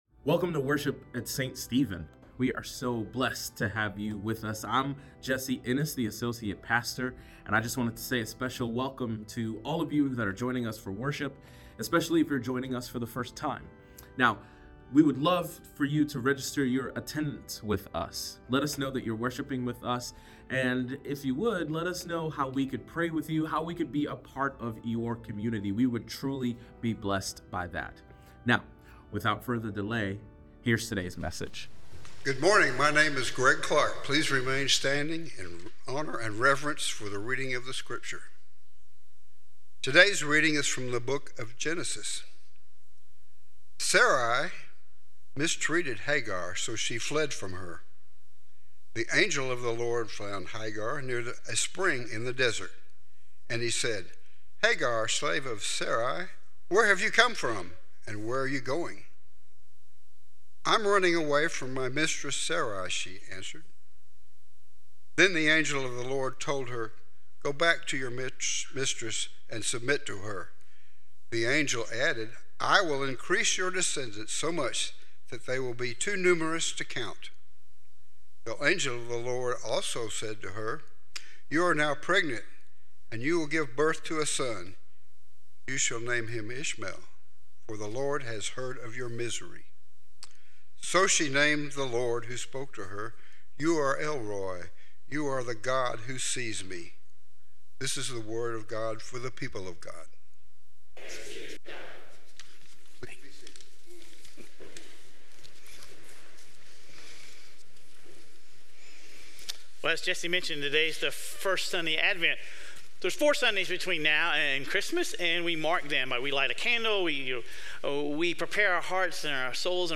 We’ll explore the various ways God’s messengers have brought people comfort, hope, and help. Sermon Reflections: How does the sermon illustrate the different roles and purposes of God's messengers, both heavenly and earthly?